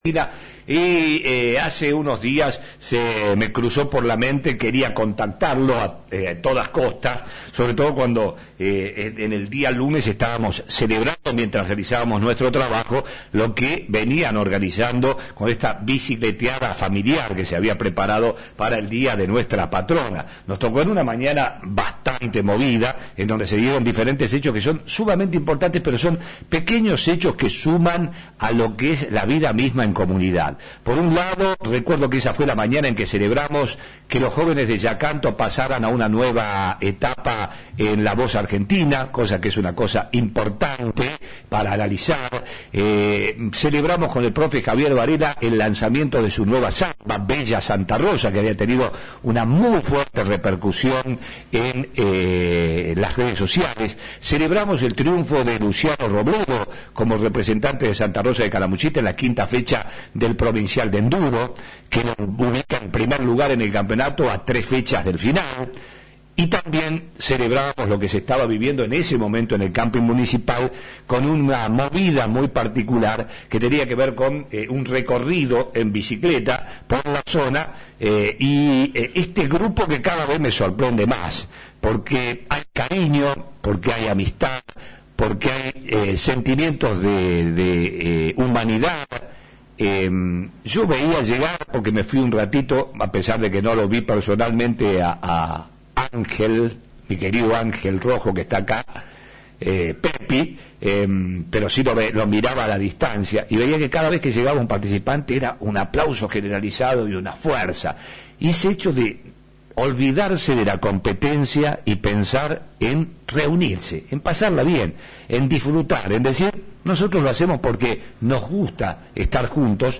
Visitó nuestros estudios